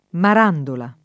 [ mar # ndola ]